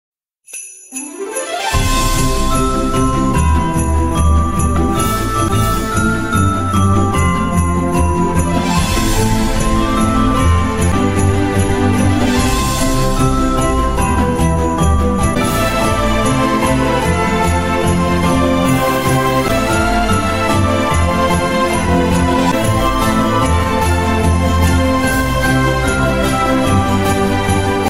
Christmas shopping at Kmart in sound effects free download